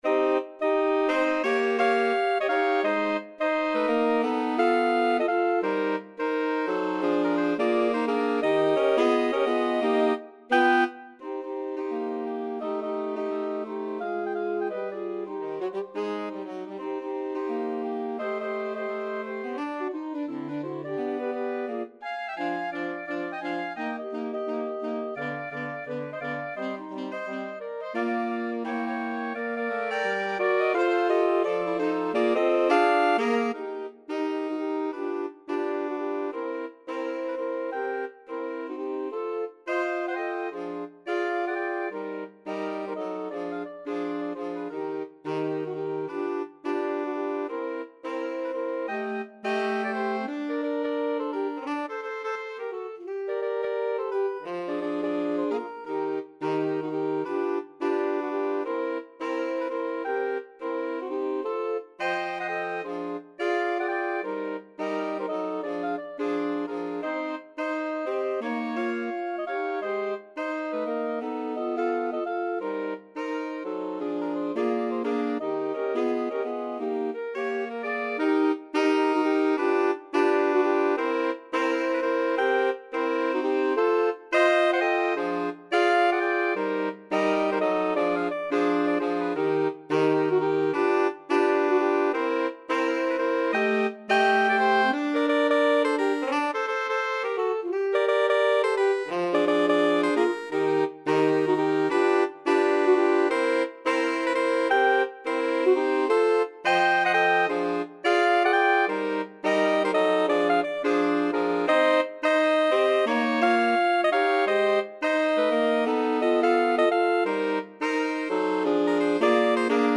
2/2 (View more 2/2 Music)
Animato =86
Jazz (View more Jazz Saxophone Quartet Music)